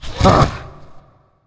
spawners_mobs_balrog_attack.ogg